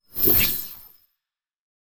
Free Frost Mage - SFX
ice_bolt_21.wav